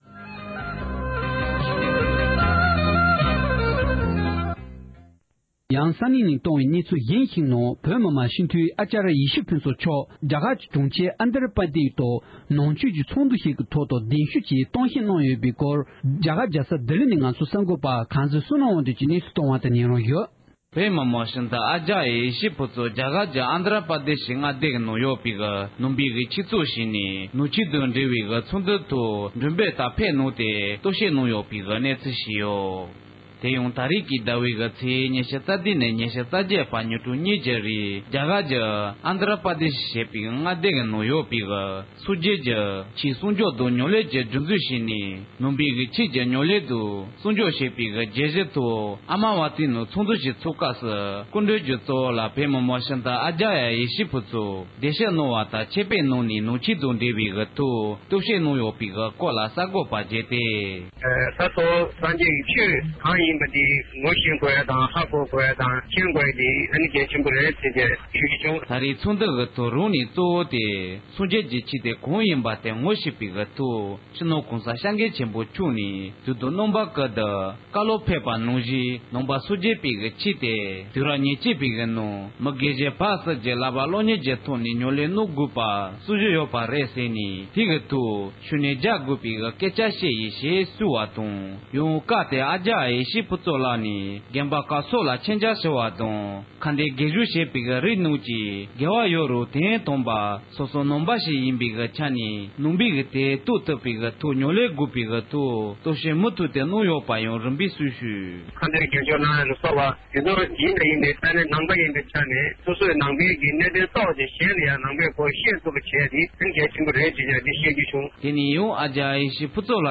བོད་མི་མང་སྤྱི་འཐུས་ཨ་ཅ་རི་ཡ་ཡེ་ཤེས་ཕུན་ཚོཊ་ལཊ་ཀྱིས་རྒྱ་གར་གྱི་མངའ་སྡེ་ཨན་དྲ་པྲ་དེཤ་རུ་ནང་ཆོས་སྐོར་གསུང་བཤད་གནང་བ།
སྒྲ་ལྡན་གསར་འགྱུར།